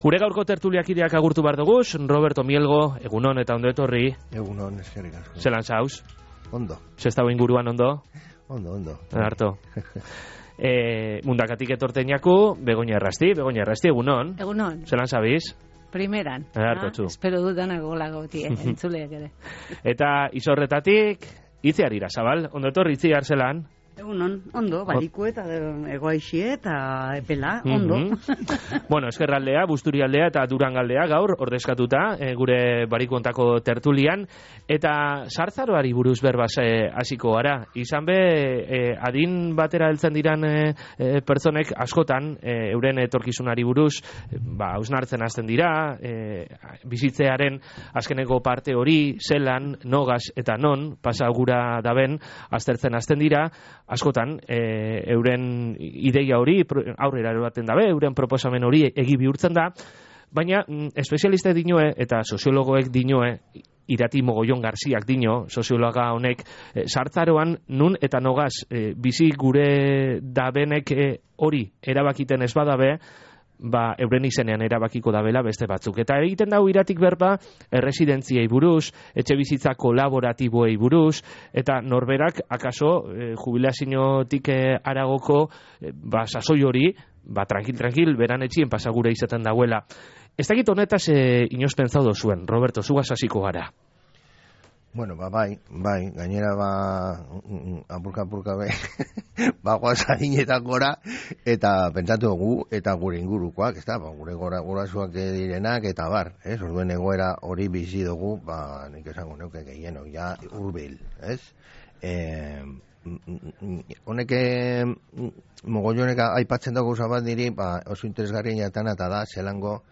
TERTULIA.mp3